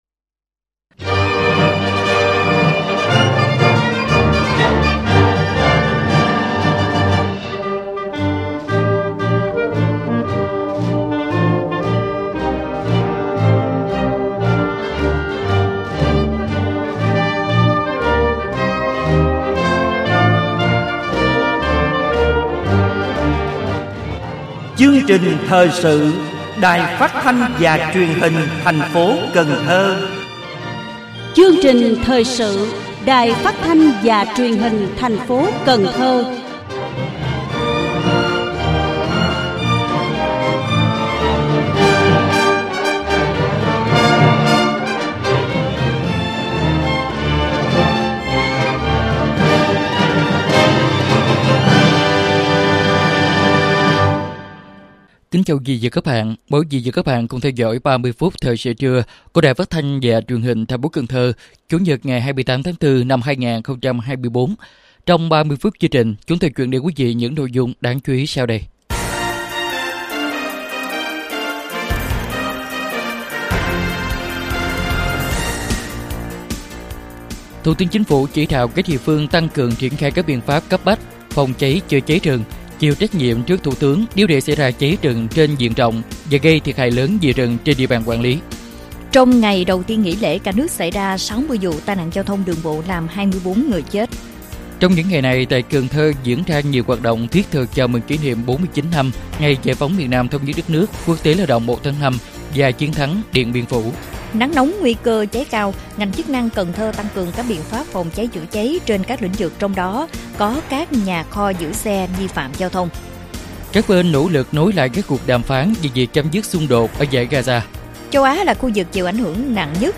Thời sự phát thanh trưa 28/4/2024